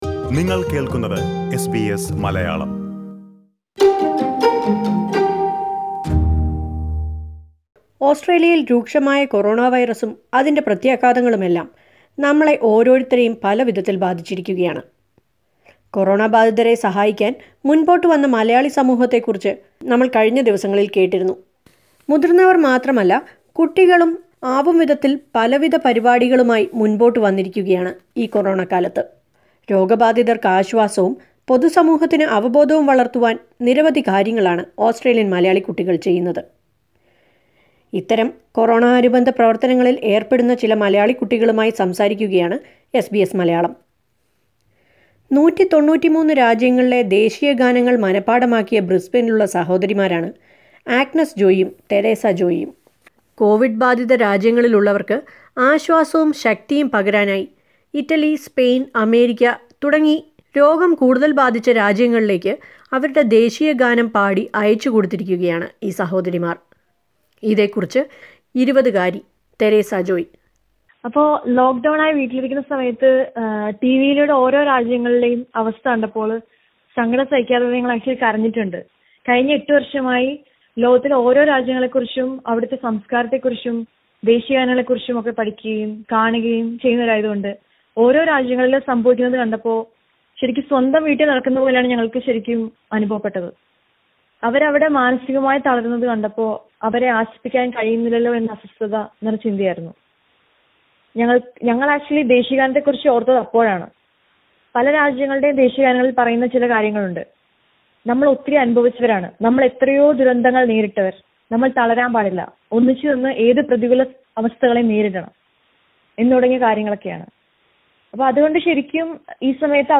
Besides adults, Malayalee kids have also come forward to extend their support to those affected by coronavirus. Listen to a report on this...